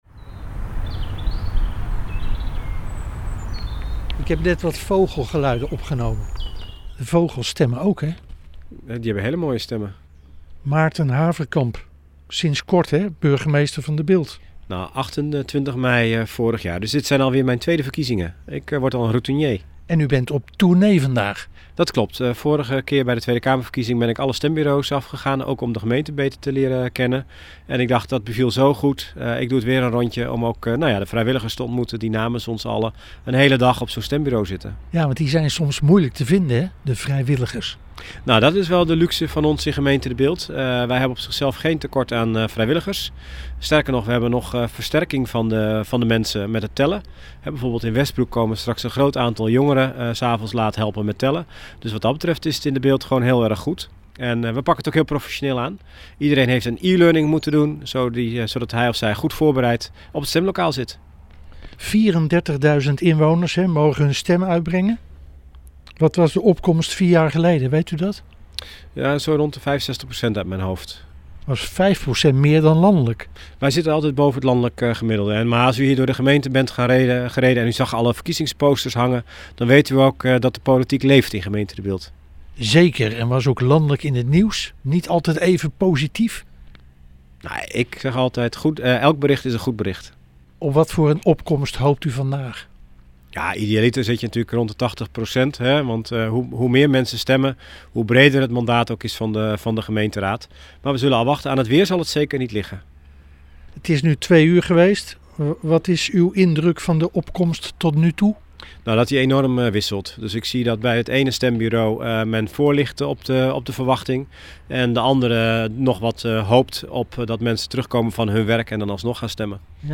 Burgemeester Maarten Haverkamp bezocht in zijn gemeente De Bilt verschillende stembureaus in de zes kernen.